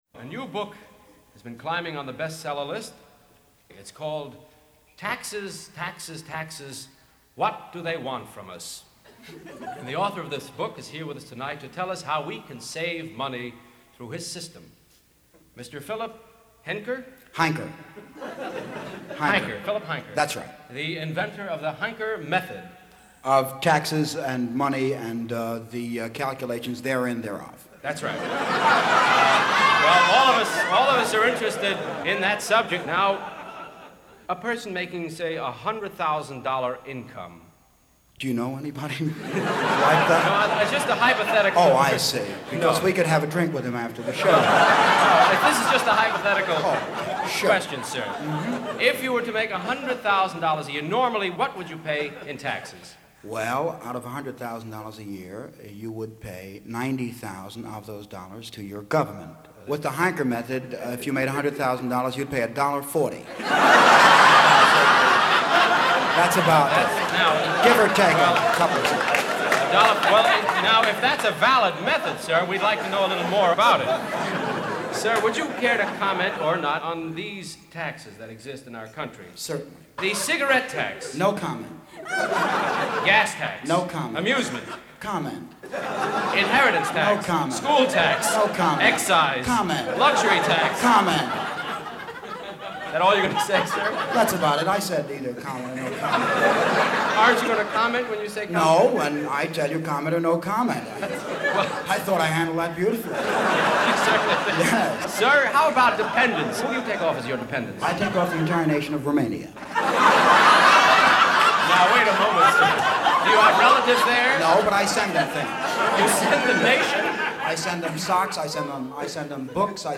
Mel Brooks as The Tax Expert
One of them I found was this bit that was recorded on 5/26/1961 from the Mel Brooks & Carl Reiner album, “2000 & One Years.